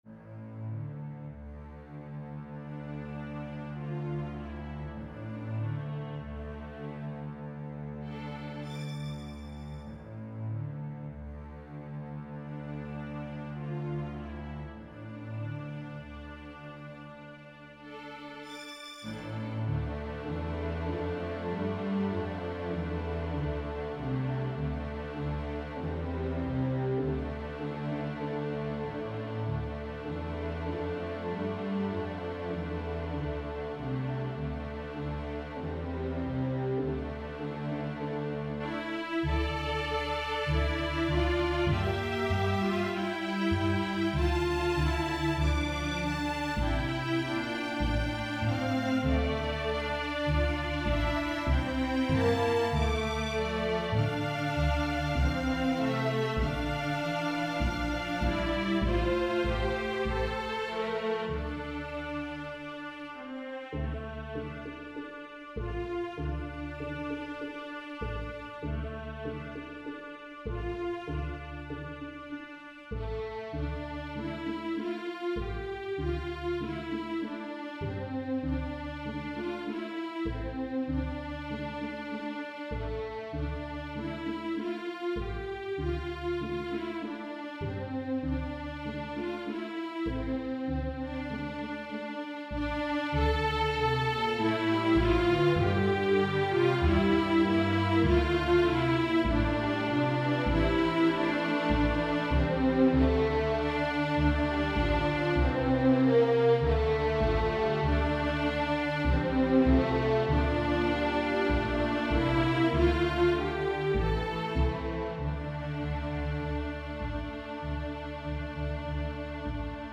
INTERMEDIATE, STRING QUARTET
Notes: natural harmonics, artificial harmonics
double stops, pizz
Key: D major